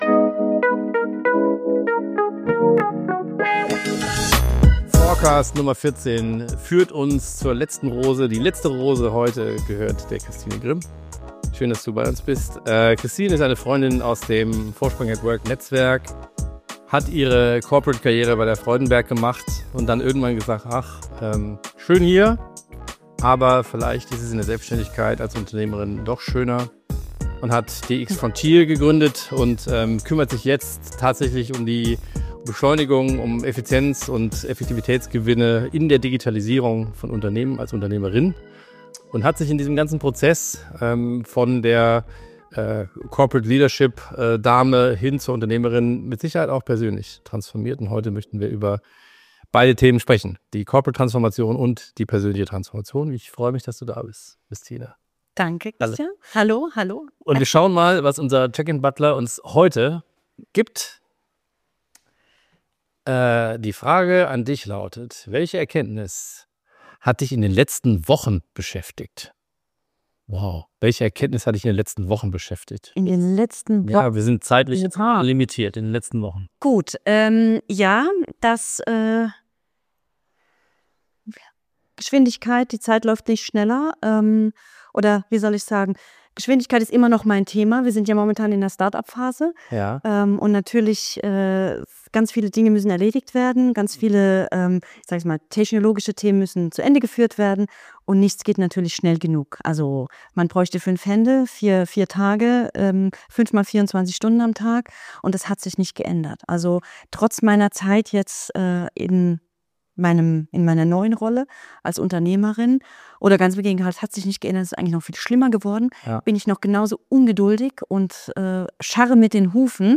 Ein Gespräch für alle, die endlich aufhören wollen, Transformation zu simulieren – und anfangen wollen, sie zu leben.